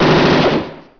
F�r jeden der einen kleinen Vorgeschmack auf dieses m�chtige Spielzeug m�chte, hier der Herzschlag der V/X Robocop.